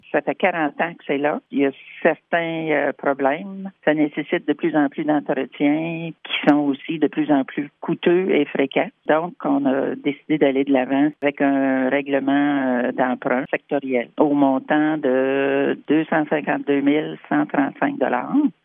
Un avis de motion et un projet de règlement ont été déposés lors du conseil municipal dans le but de remplacer la station de pompage des eaux usées dans le secteur du Mont Ste-Marie. Cheryl Sage-Christensen, mairesse de Lac-Sainte-Marie, explique pourquoi le changement est nécessaire :